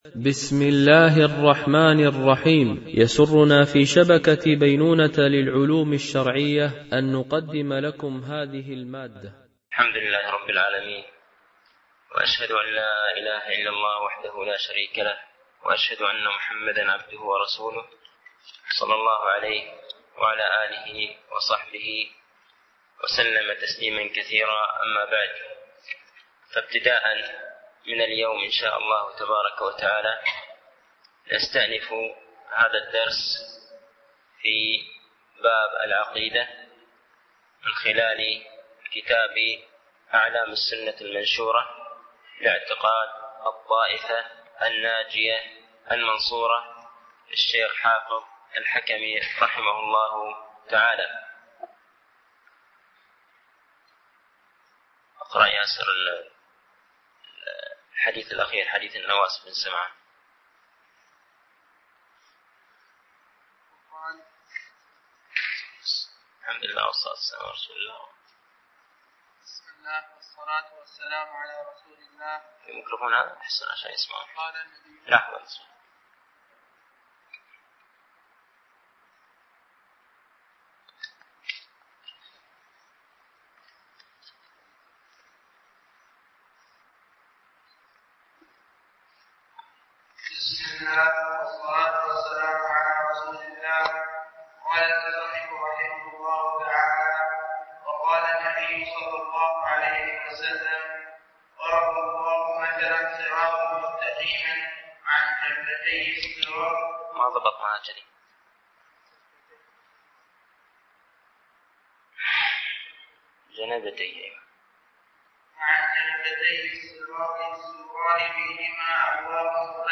) الألبوم: شبكة بينونة للعلوم الشرعية المدة: 54:17 دقائق (12.46 م.بايت) التنسيق: MP3 Mono 22kHz 32Kbps (CBR)